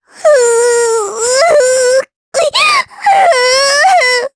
Cleo-Vox_Sad_jp.wav